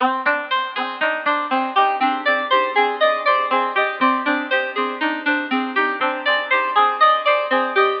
LOOP 3 120bpm
Tag: 120 bpm Trap Loops Synth Loops 1.35 MB wav Key : Unknown